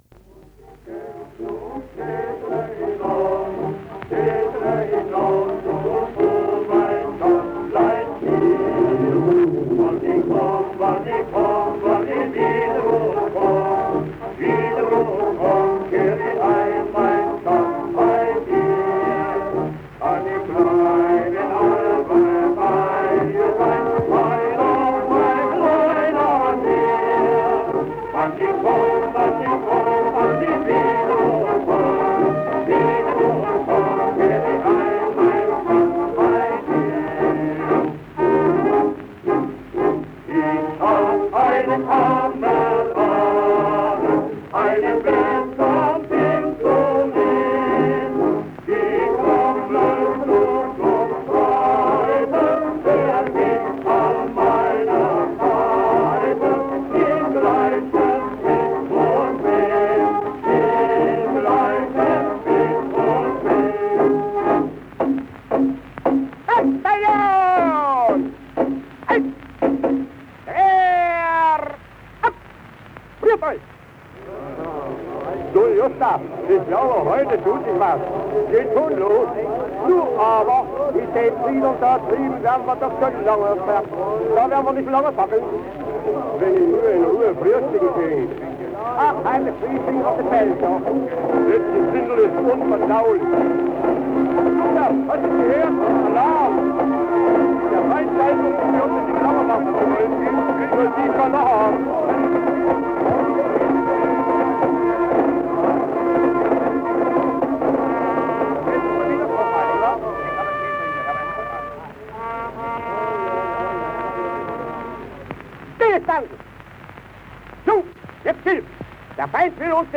German propaganda recording early in World War I about the fall of the city of Liège in Belgium